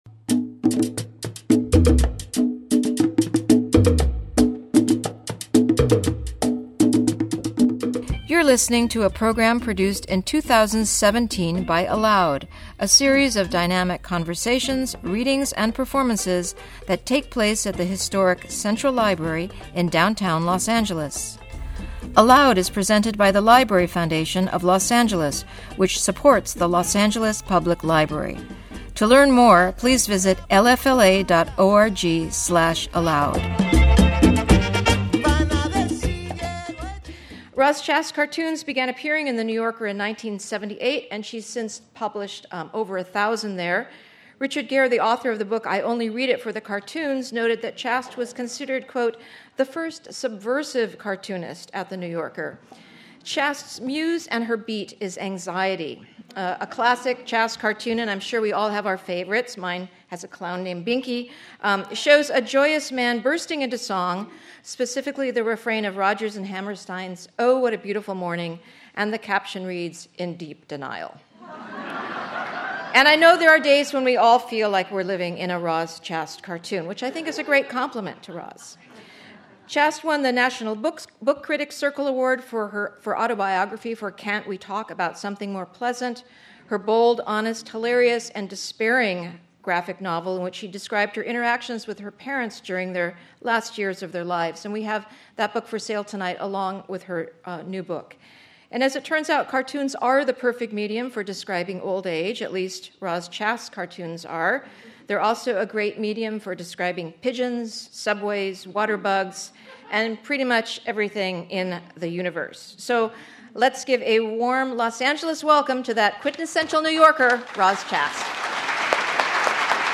Artist talk and Q&A